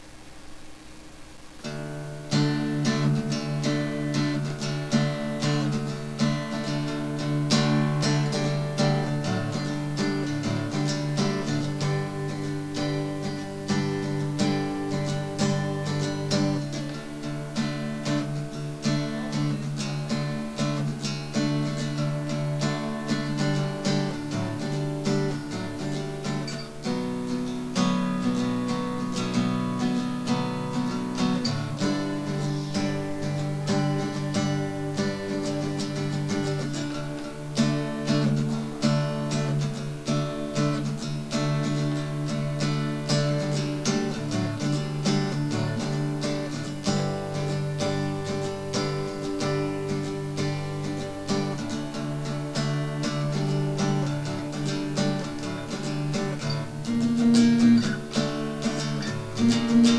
rhythm guitar